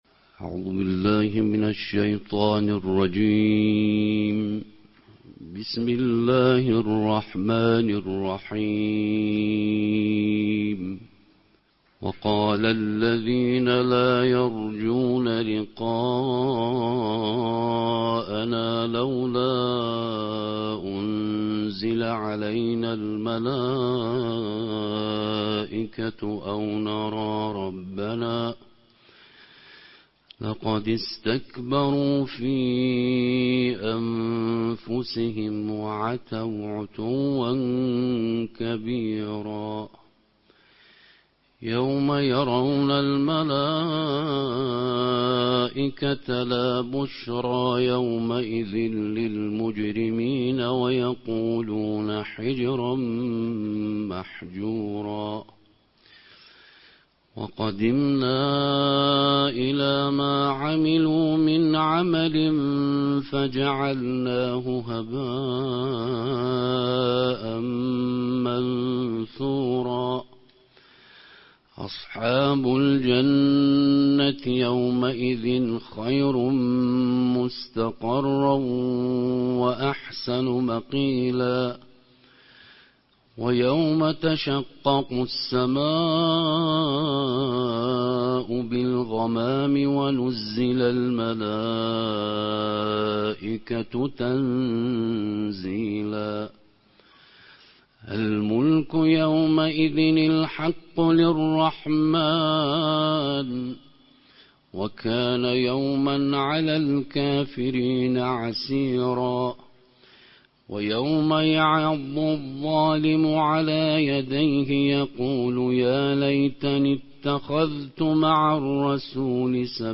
نړیوال قارِيان ،د قرآن کریم د نولسمې(۱۹) سپارې یا جزوې د ترتیل قرائت